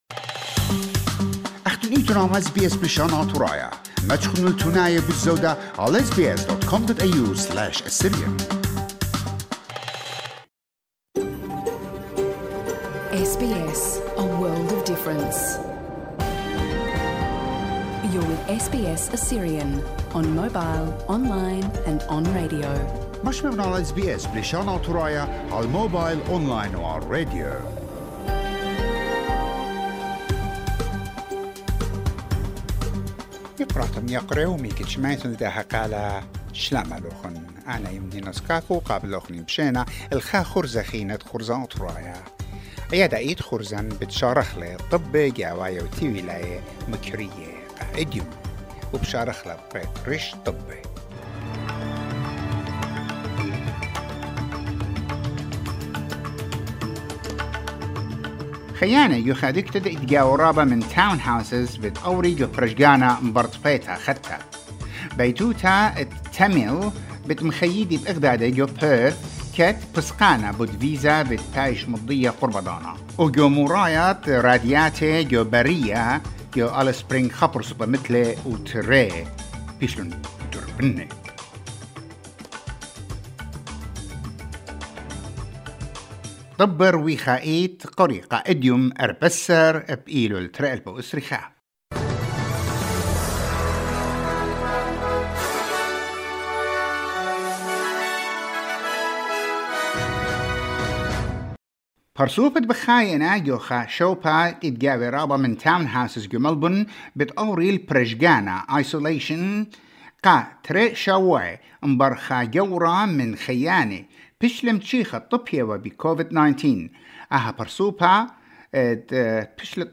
NEWS BULLETIN TUESDAY 14 SEPTEMBER 2021